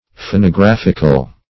Search Result for " phonographical" : The Collaborative International Dictionary of English v.0.48: Phonographic \Pho`no*graph"ic\, Phonographical \Pho`no*graph"ic*al\, a. [Cf. F. phonographique.] 1.
phonographical.mp3